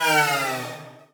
UIMvmt_Power Down Denied 01.wav